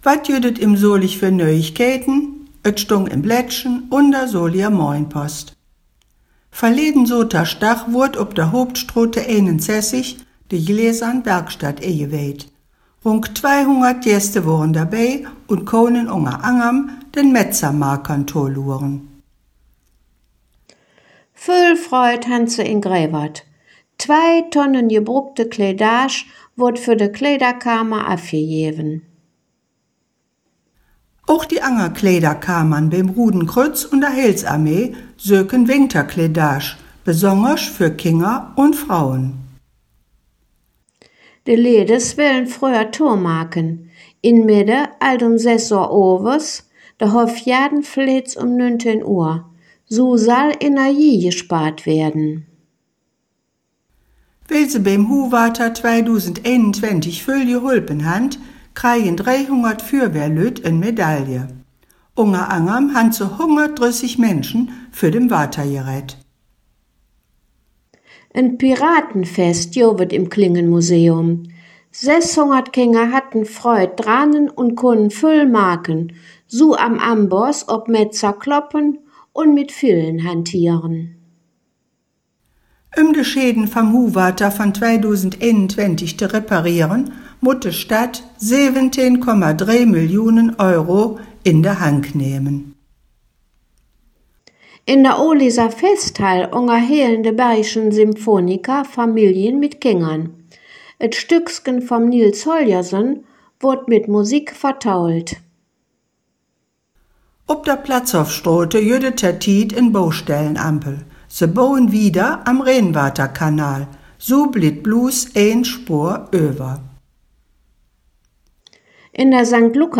Nöüegkeïten op Soliger Platt Dös Weeke em Solig (22/43)